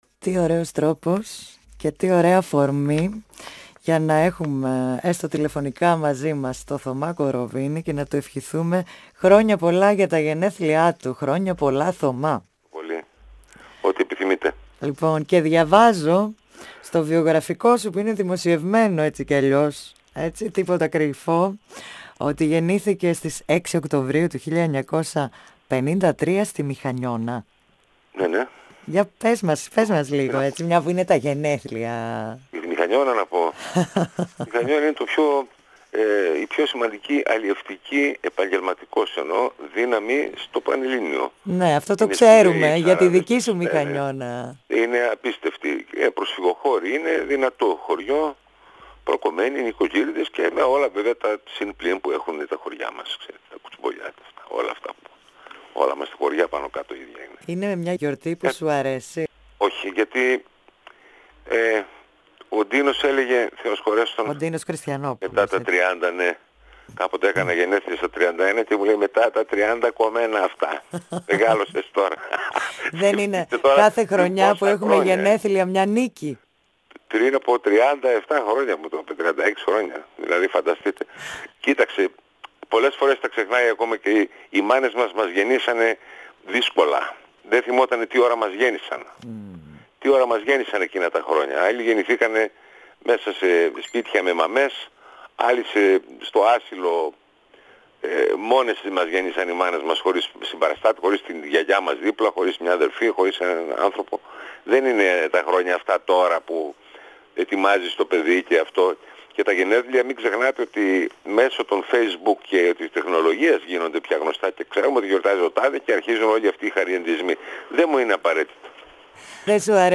Ο Θωμάς Κοροβίνης μίλησε στον 9.58 της ΕΡΤ3